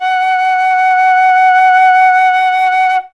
51c-flt20-F#4.wav